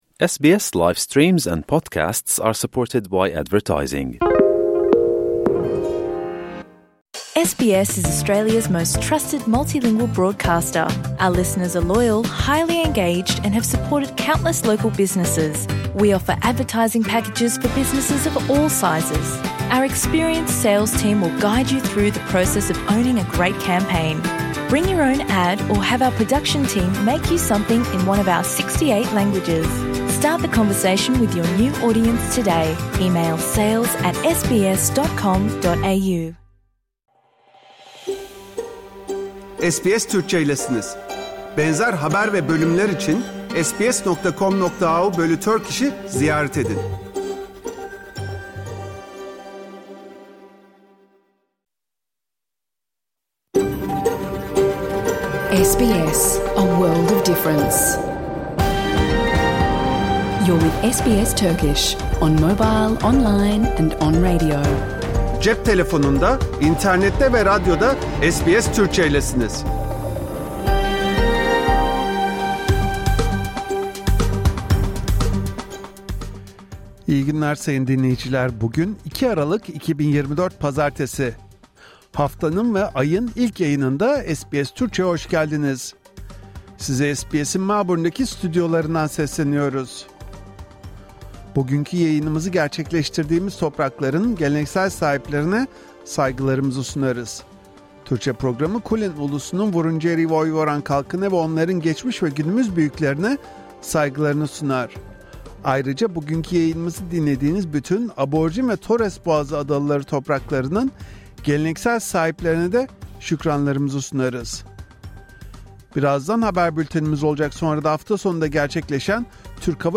Hafta içi Salı hariç her gün Avustralya doğu kıyıları saati ile 14:00 ile 15:00 arasında yayınlanan SBS Türkçe radyo programını artık reklamsız, müziksiz ve kesintisiz bir şekilde dinleyebilirsiniz.
🎧 PROGRAM İÇERİĞİ SBS Türkçe Haber Bülteni Avustralya Türk toplumu tarafından 1991'den bu yana beklenen Türk Hava Yolları'nın ilk İstanbul-Sydney uçağı indi.